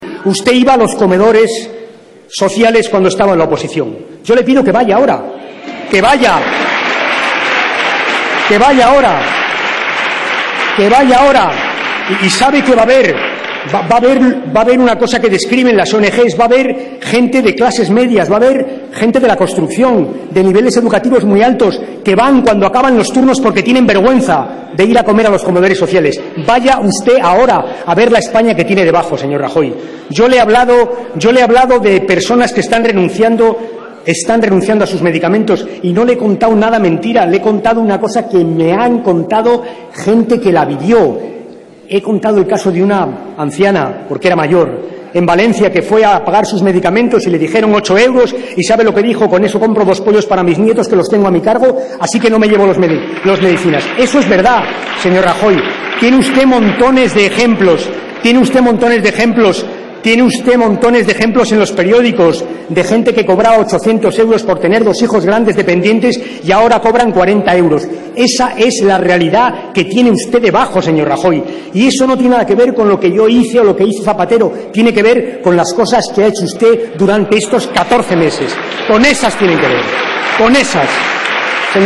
Alfredo P. Rubalcaba. Debate del Estado de la Nación 20/02/2013